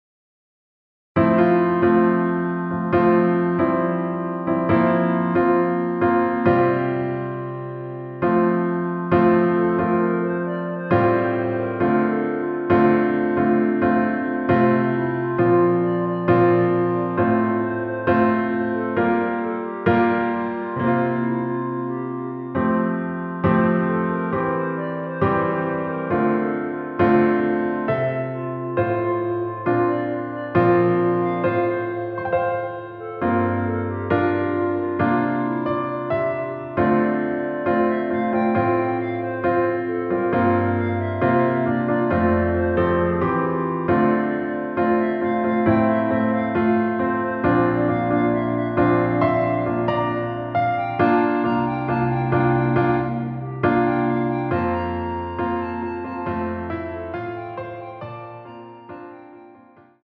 반주를 피아노 하나로 편곡하여 제작하였습니다.
원키에서(+4)올린 (Piano Ver.)멜로디 MR입니다.